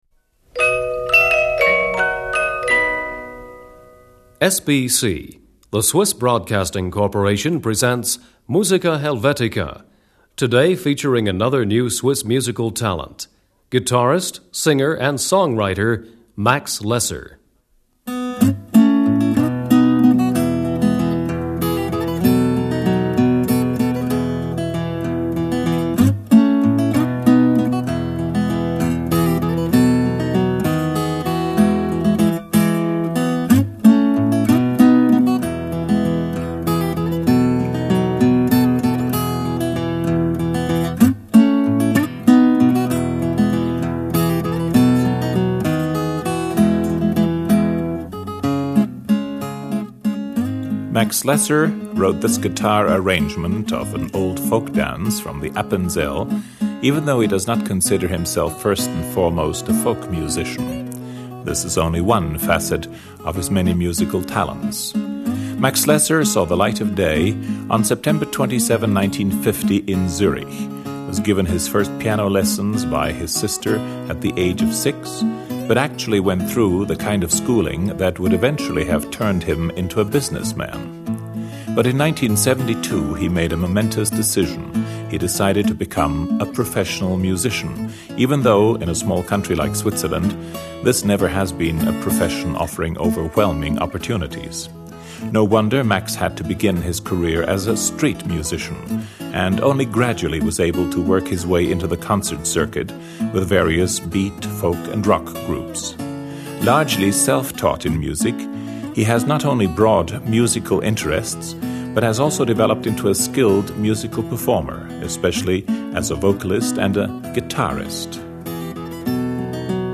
Traditional Appenzel waltz.
Traditional Engadine dance.
clarinet.
bass.
organ.
piccolo.
drums.
electric piano.
saxophone.
harp.